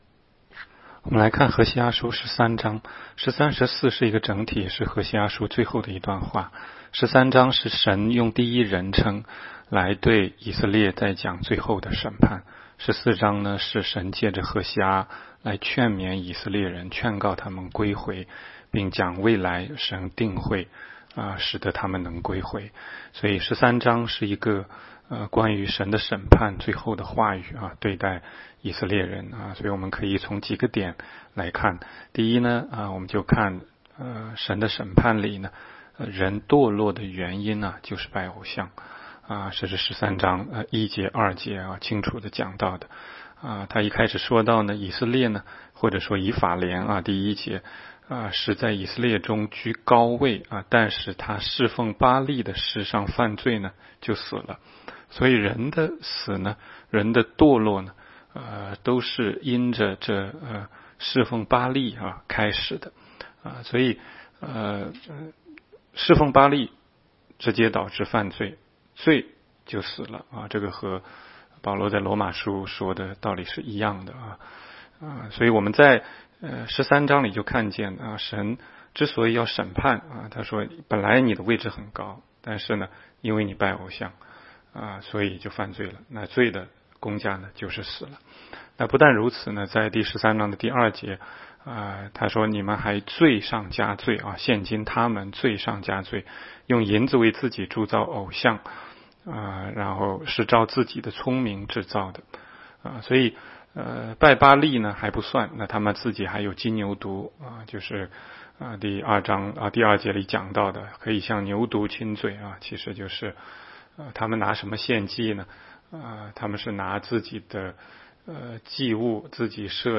16街讲道录音 - 每日读经 -《何西阿书》13章